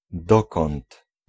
Ääntäminen
IPA : /ˈwɛɚ/